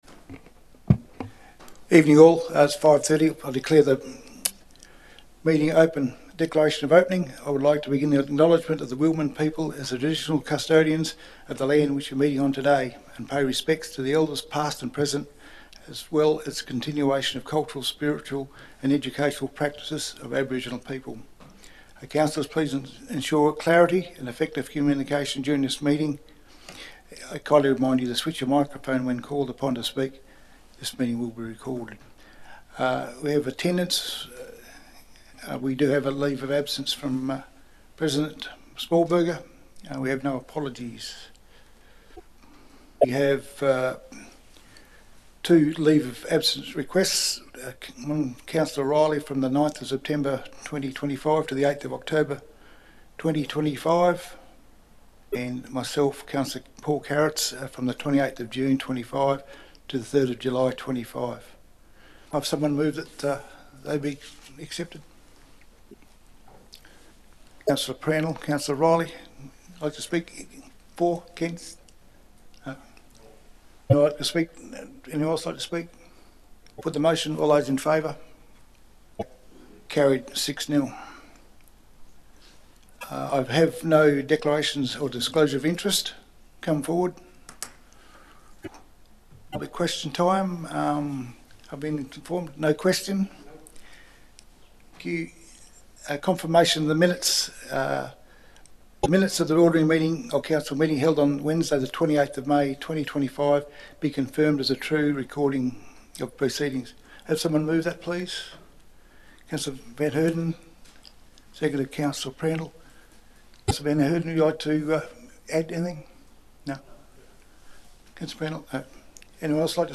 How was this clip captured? Ordinary Council Meeting June 2025 » Shire of Boddington